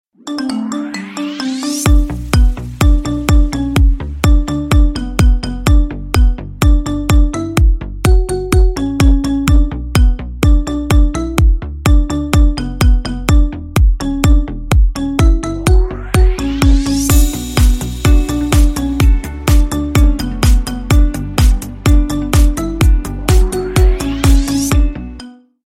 Рингтоны Без Слов